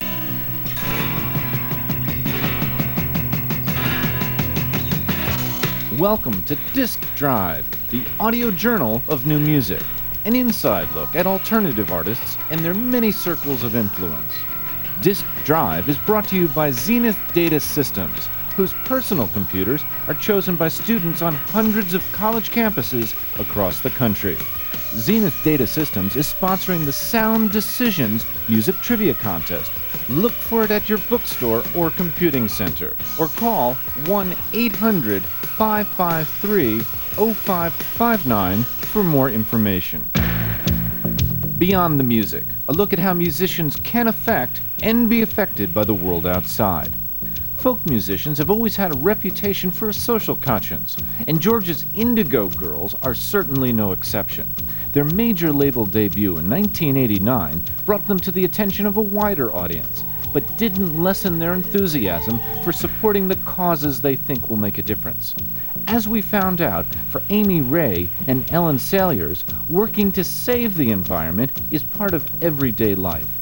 (acoustic duo show)
01. intro (1:13)